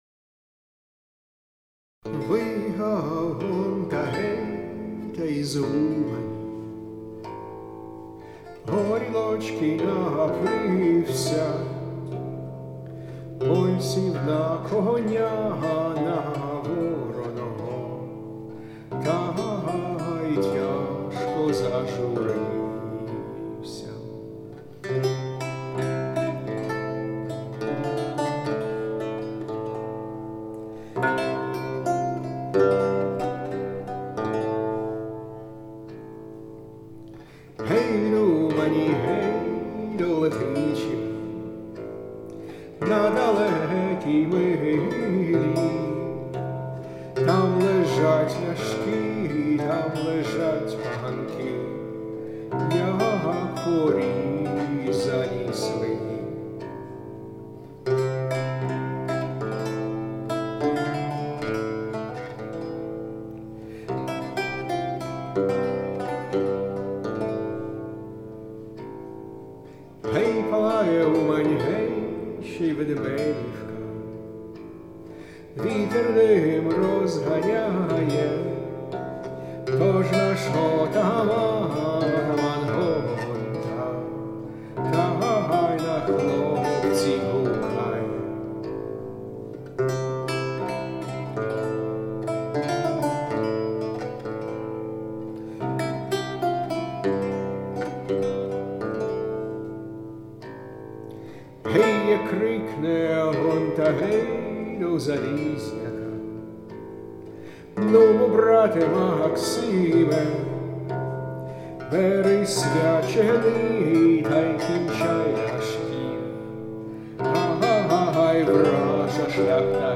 played and "sung" by yours truly.